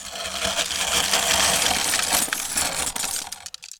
ice_spell_freeze_ground_03.wav